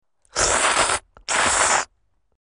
吃面条吸面条声音效免费下载素材
SFX音效